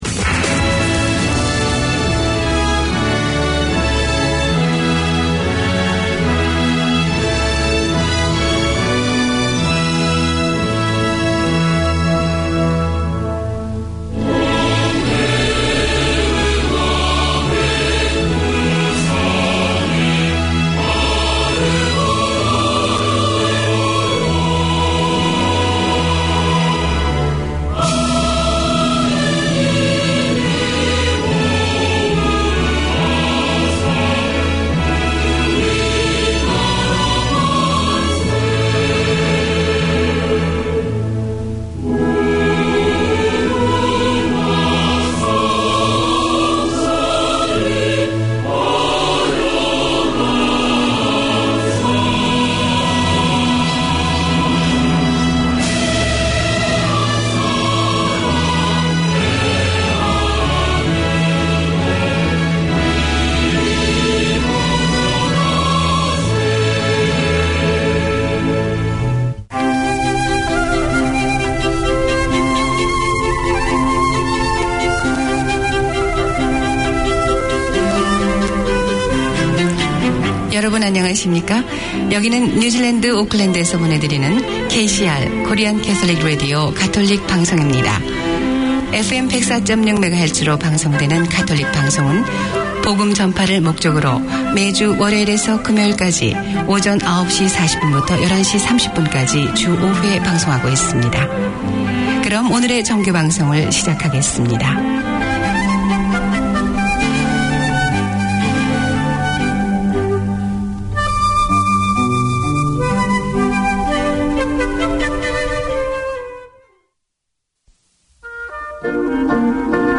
Presented by an experienced English teacher who understands the needs of his fellow migrants, these 30 minute lessons cover all aspects of English including Pronunciation, Speaking, Listening, Reading and Writing. Hear how to improve English for general and workplace communication, social interactions, job interviews, IELTS and academic writing, along with interviews with migrants and English experts.